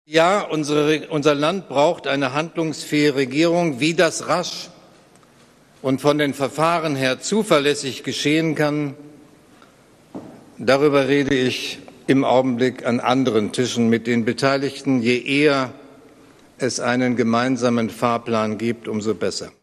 Nachrichten Steinmeier: „Land braucht handlungsfähige Regierung – je eher um so besser“